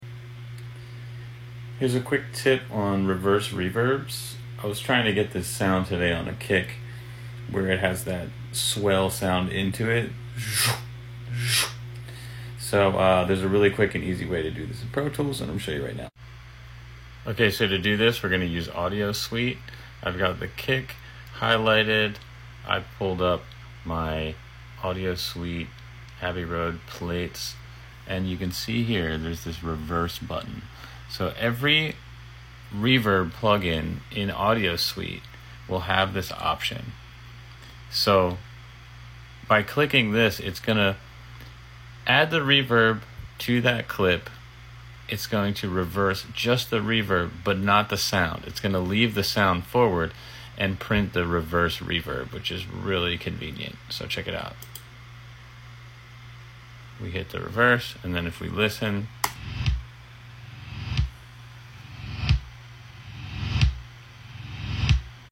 Reverse reverb! Let me know sound effects free download